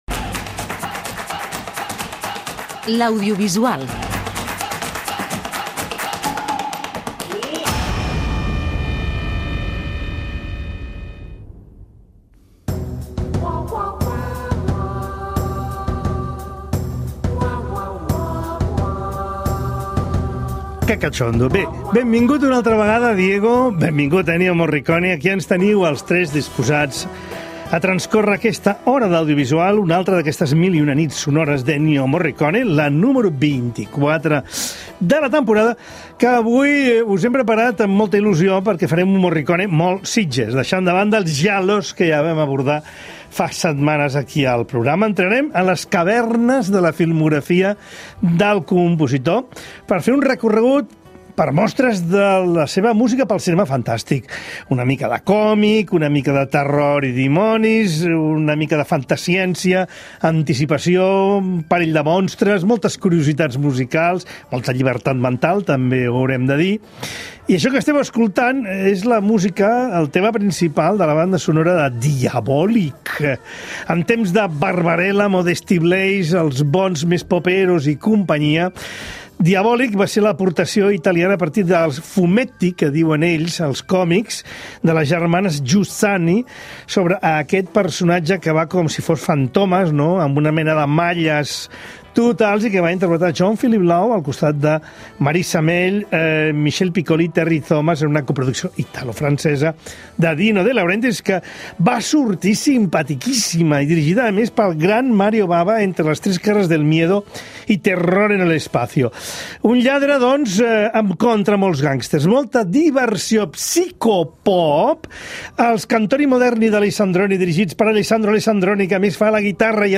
bandes sonores
Podem sentir les bandes sonores de films